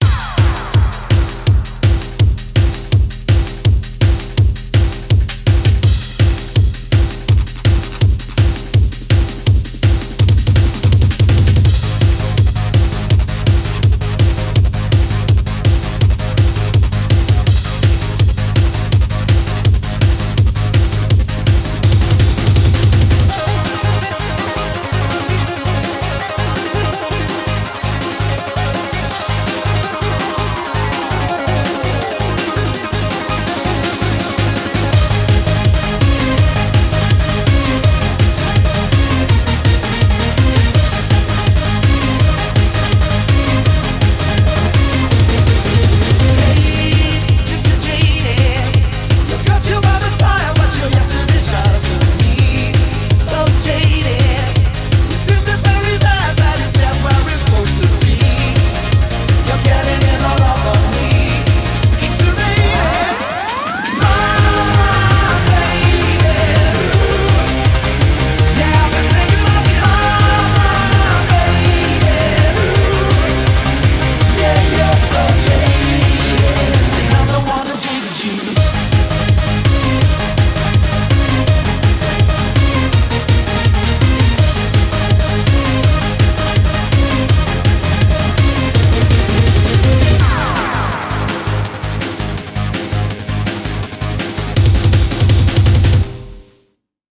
BPM165
Audio QualityMusic Cut
Speed cover of a famous rock song